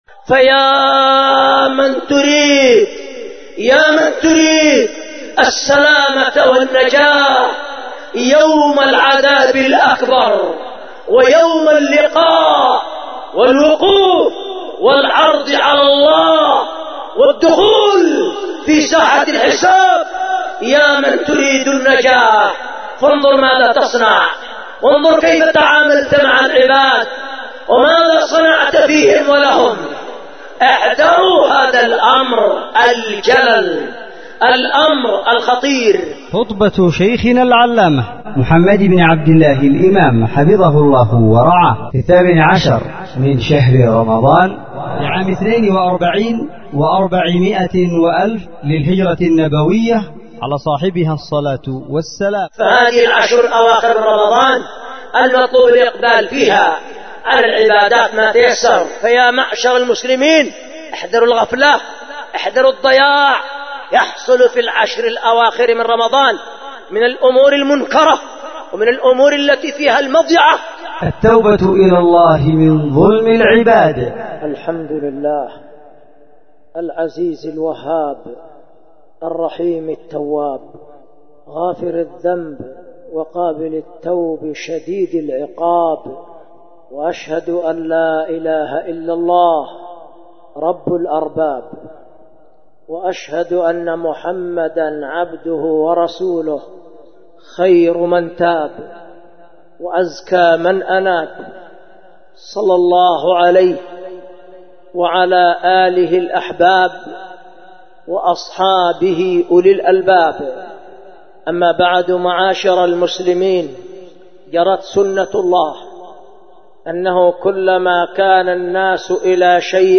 التوبة إلى الله من ظلم العباد التوبة إلى الله من ظلم العباد خُطبة لفضيلة الشيخ العلامة
ألقيت بـ دار الحديث بمعبر حرسها الله ذمار_اليمن